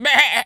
goat_baa_stressed_hurt_05.wav